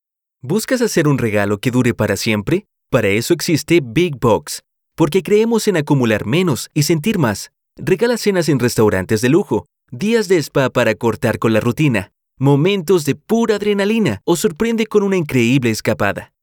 成熟男声